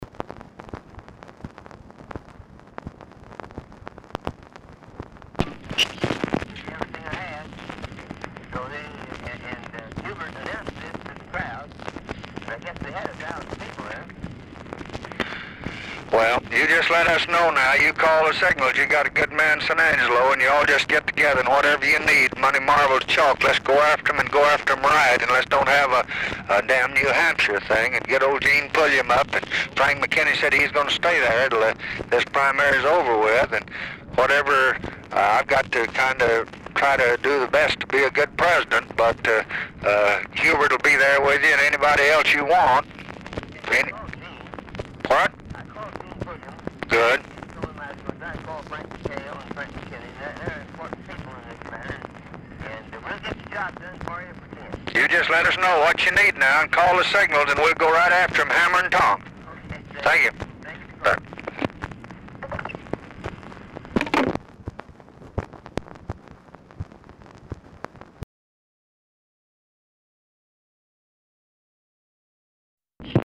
Title Telephone conversation # 12818, sound recording, LBJ and ROGER BRANIGIN, 3/17/1968, 3:00PM Archivist General Note "OF INDIANA"; "IN FT.
POOR SOUND QUALITY; BRANIGIN IS ALMOST INAUDIBLE; RECORDING STARTS AFTER CONVERSATION HAS BEGUN
Format Dictation belt